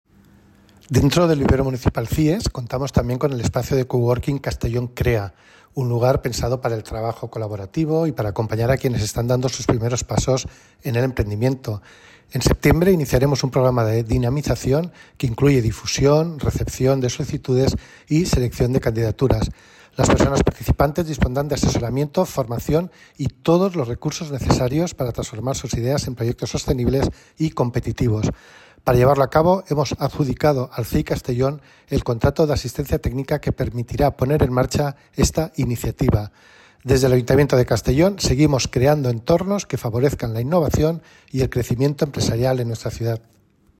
Corte de voz del concejal de Empleo en el Ayuntamiento de Castellón, Juan Carlos Redondo.